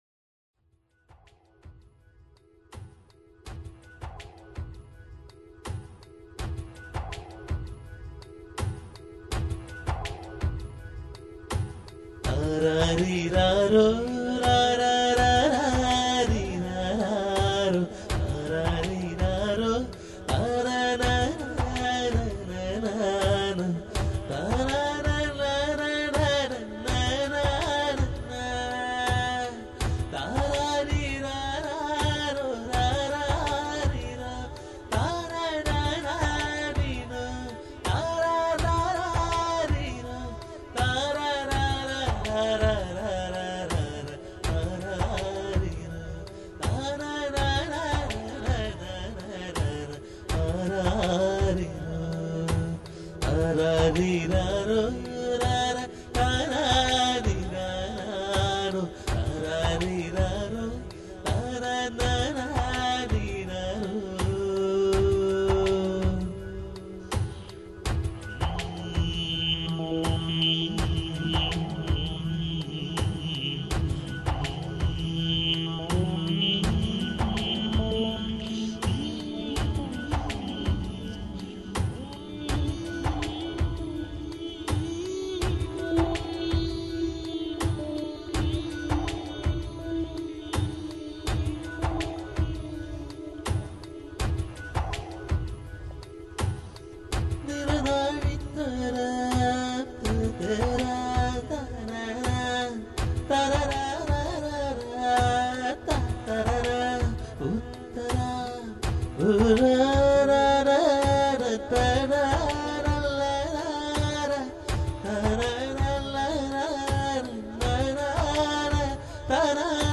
രണ്ടാം എപ്പിസോഡായ “അമ്മത്തൊട്ടിൽ” (താരാട്ട് പാട്ട്) ഇന്നു തുടങ്ങുന്നു.